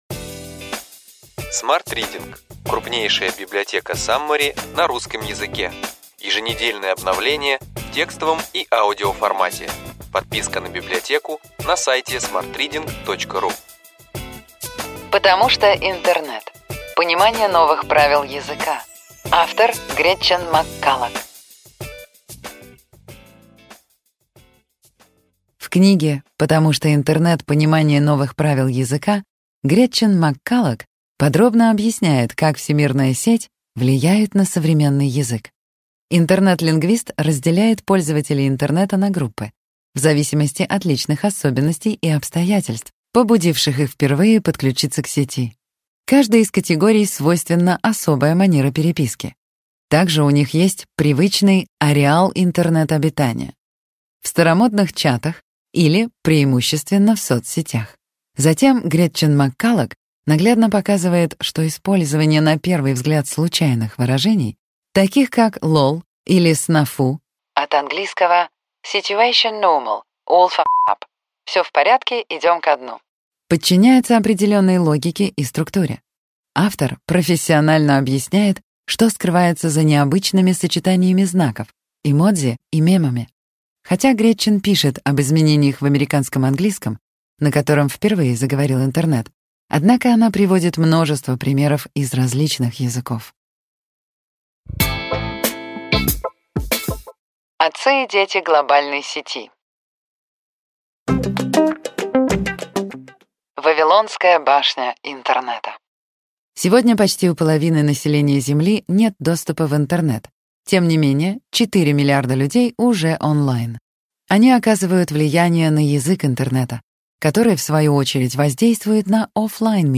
Аудиокнига Ключевые идеи книги: Потому что интернет. Понимание новых правил языка.